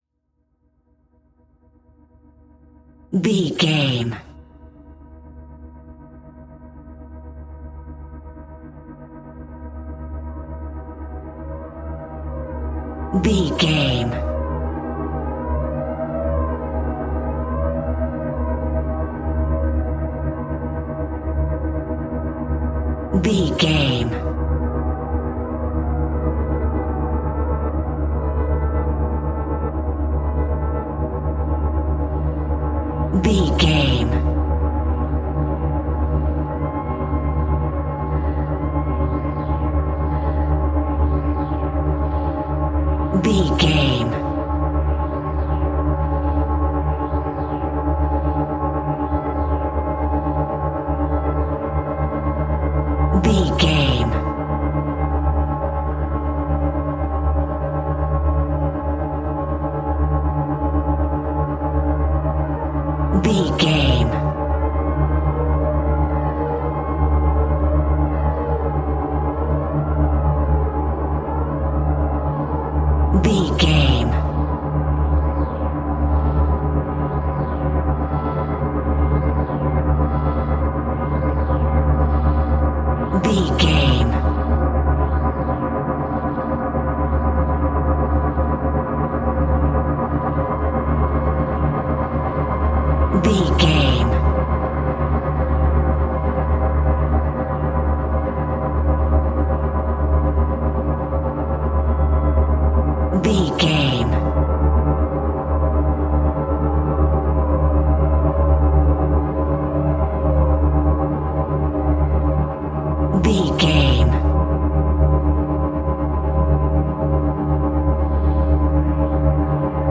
Atonal
scary
tension
ominous
dark
suspense
haunting
eerie
strings
synthesiser
ambience
pads
eletronic